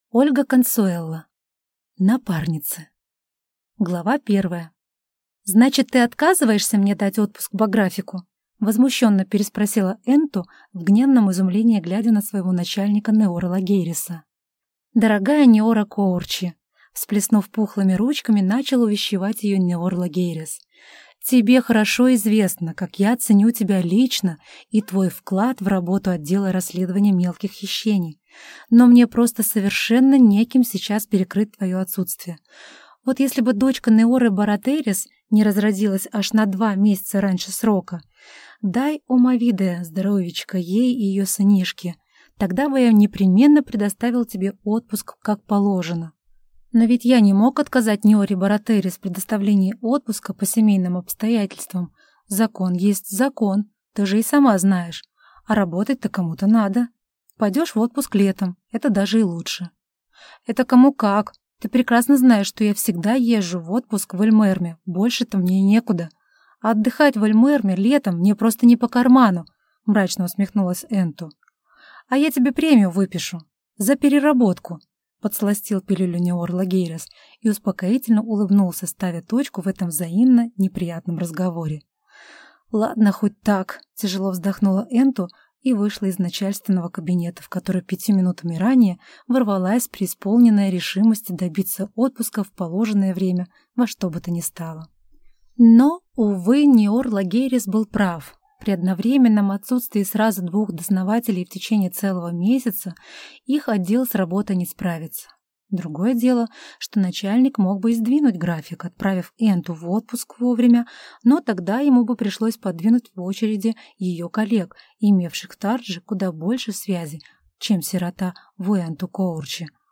Аудиокнига Напарницы | Библиотека аудиокниг
Прослушать и бесплатно скачать фрагмент аудиокниги